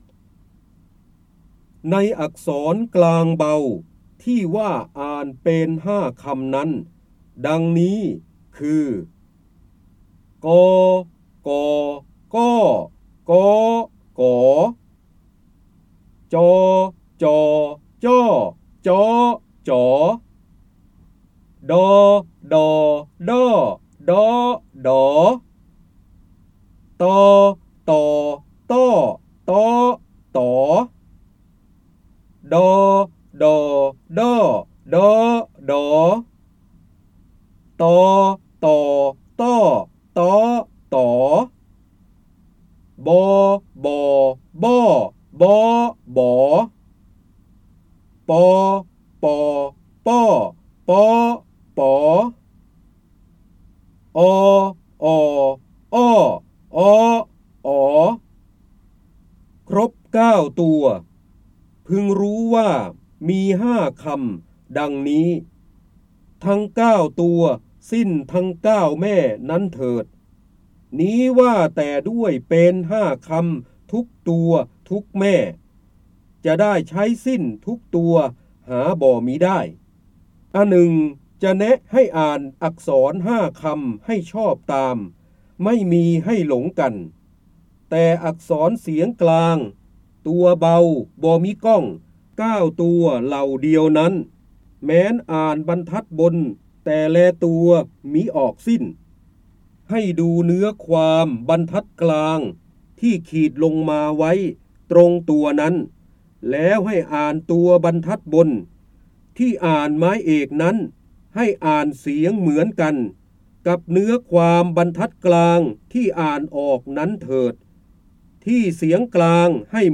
เสียงบรรยายจากหนังสือ จินดามณี (พระเจ้าบรมโกศ) ในอัการกลางเบา ที่ว่าอ่านเปน ๕ คำนั้นดงงนี้